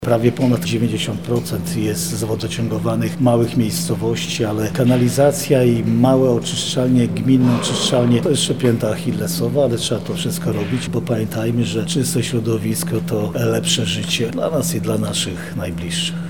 Jarosław Stawiarski– dodaje Jarosław Stawiarski.